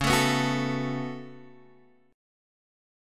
C#7sus4#5 Chord